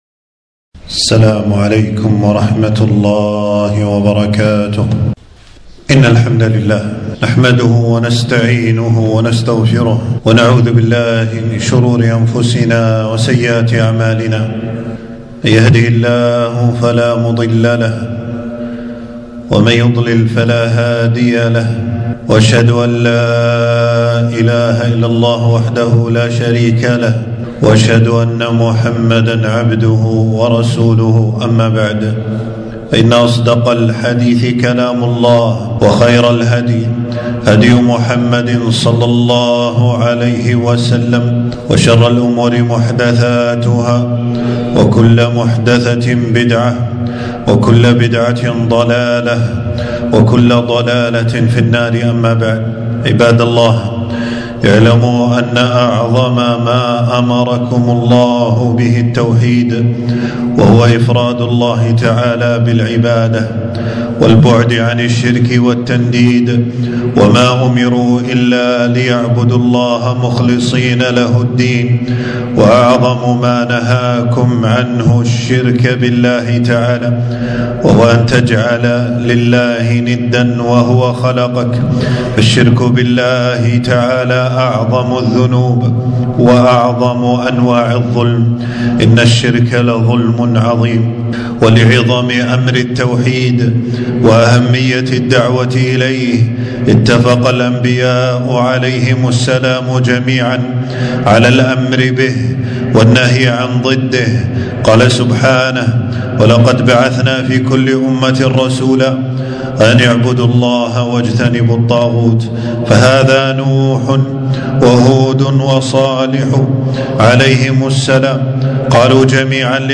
خطبة - فضل التوحيد والثناء على شيخ الإسلام محمد بن عبدالوهاب